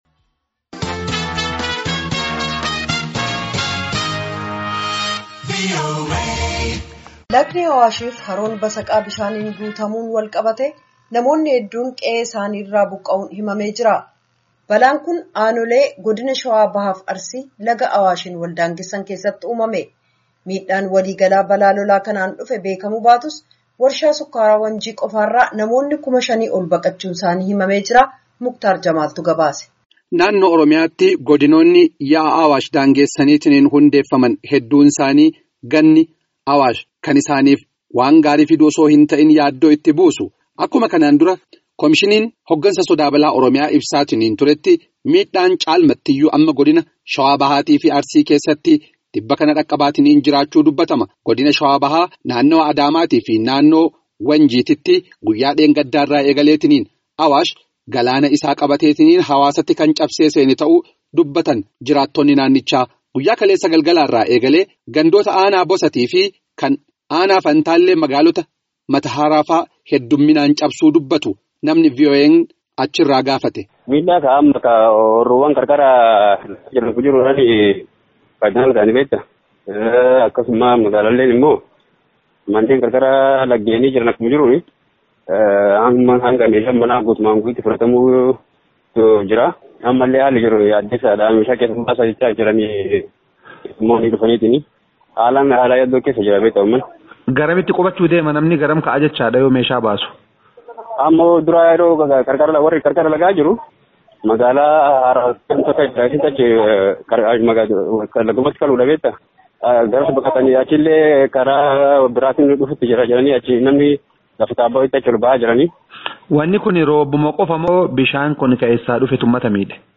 Balaa kanaan miidhaan walii-galatti mudate bekamuu baatus, Warshaa Sukkaaraa Wanjii qofa irraa namoonni kuma shanii ol buqqa’uu tu himame. Gabaasaa guutuu caqasaa.